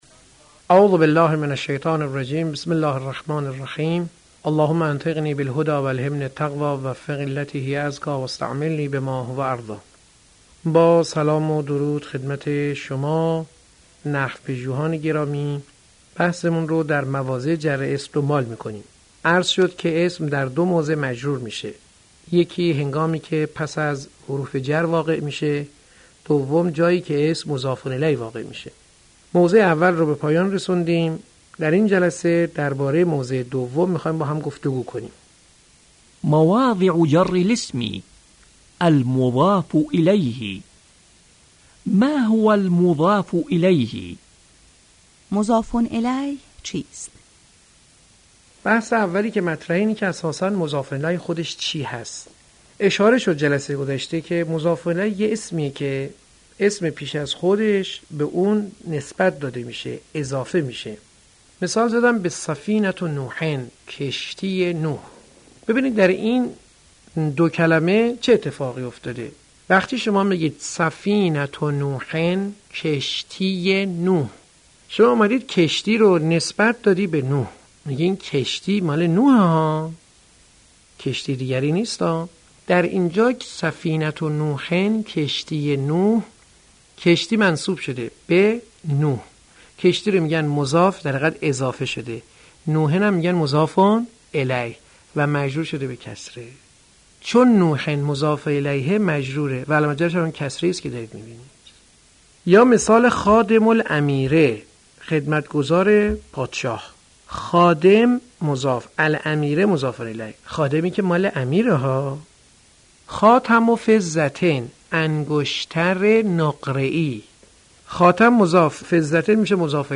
در این بخش جلسات تدریس مبادی العربیه